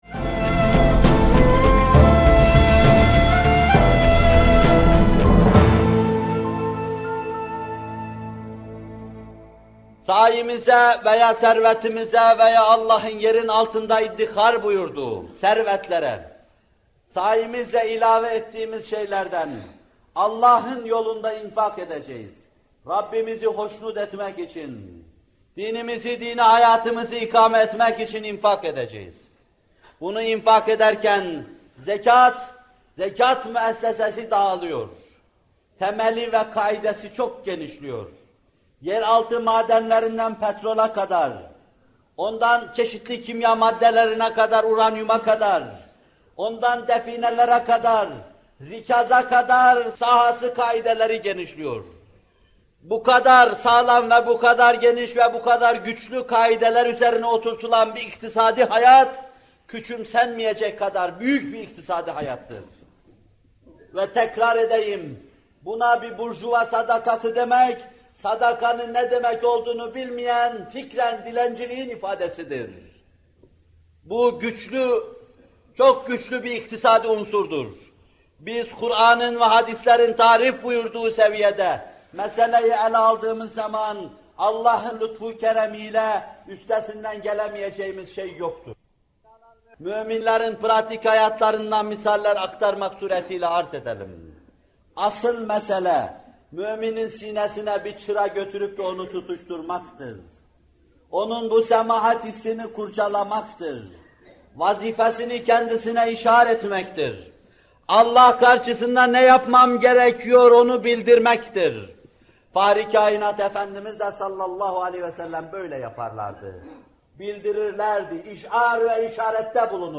Zekat Vaazları – Sahabenin ve Bugünün İnfak Destanlığının Temelinde Gönül Durulukları Vardır..